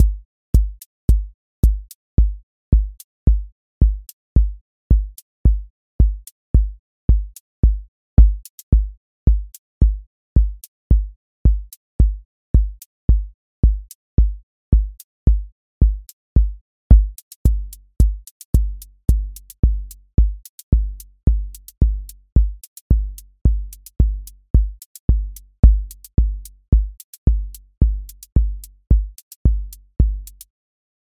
Amber Dust QA Listening Test house Template: four_on_floor April 18, 2026 ← Back to all listening tests Audio Amber Dust Open MP3 directly Selected Components macro_house_four_on_floor voice_kick_808 voice_hat_rimshot voice_sub_pulse Test Notes What This Test Is Amber Dust Selected Components macro_house_four_on_floor voice_kick_808 voice_hat_rimshot voice_sub_pulse